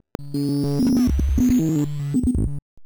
Glitch FX 40.wav